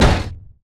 IMPACT_Generic_03_mono.wav